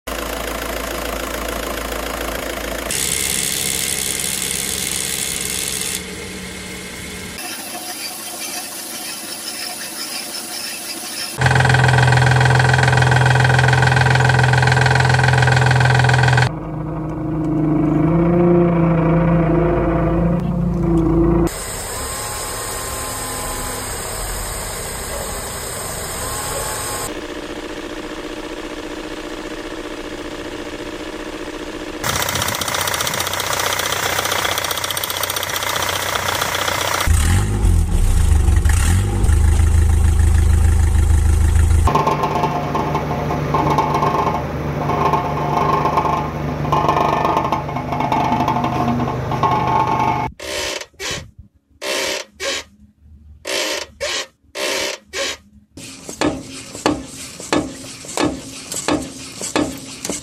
Car problems sounds part 9 sound effects free download
car problem sounds engine noise issues vehicle troubleshooting strange car noises car diagnostic engine knocking transmission noise brake failure sounds suspension problems exhaust noise weird car sounds